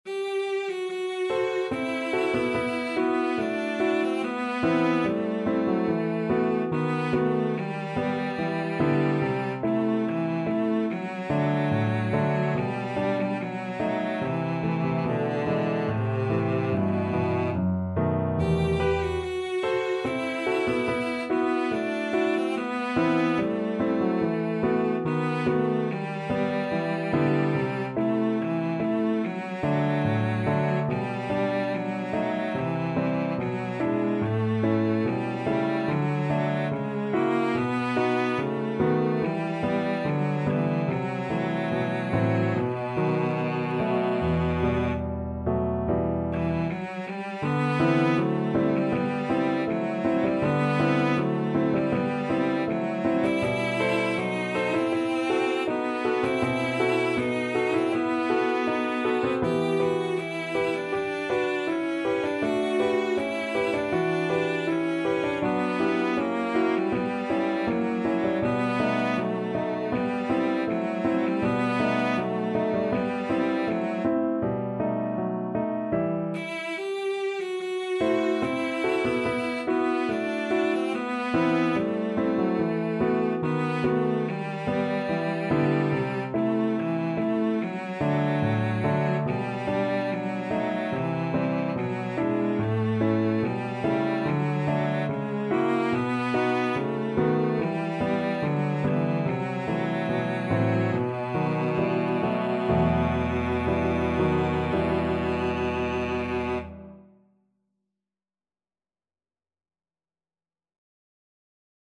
2/4 (View more 2/4 Music)
~ = 72 Andantino (View more music marked Andantino)
Cello  (View more Intermediate Cello Music)
Classical (View more Classical Cello Music)